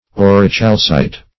Search Result for " aurichalcite" : The Collaborative International Dictionary of English v.0.48: Aurichalcite \Au`ri*chal"cite\, n. [See Aurichalceous .]